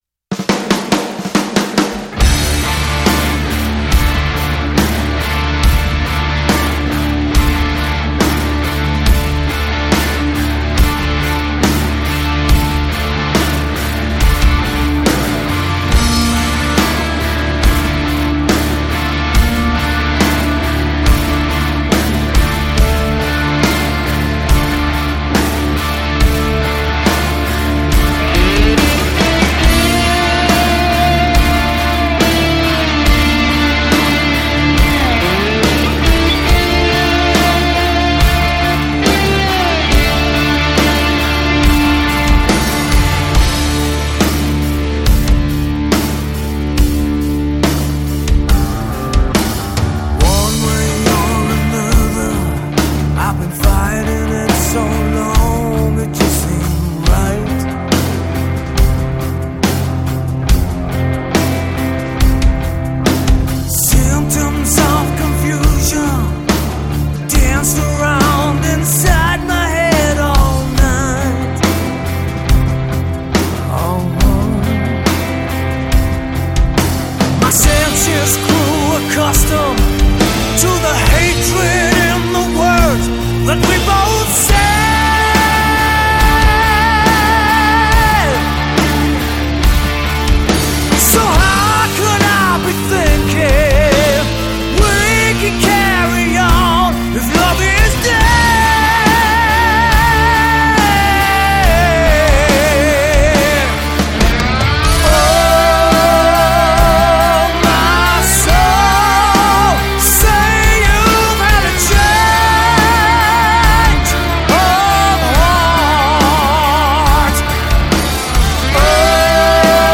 Жанр: Rock/Metal